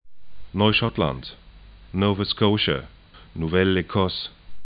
nɔy'ʃɔtlant